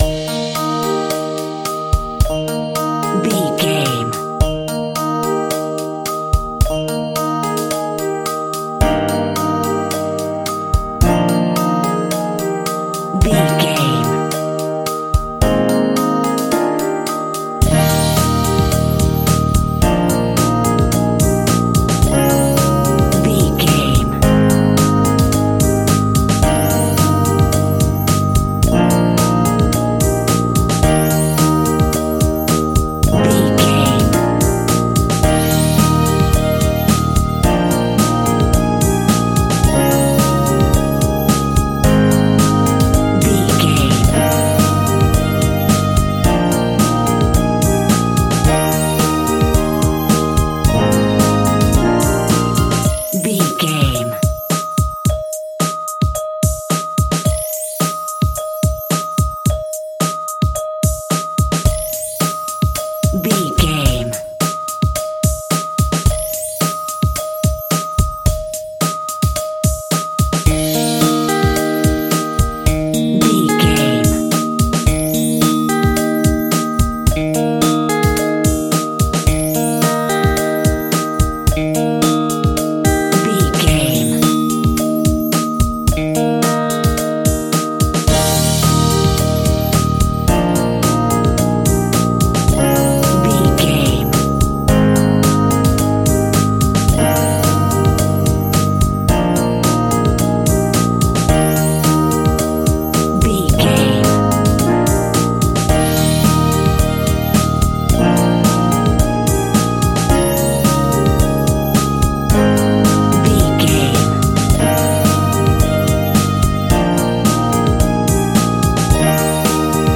Uplifting
Ionian/Major
E♭
childrens music
drums
bass guitar
electric guitar
piano
hammond organ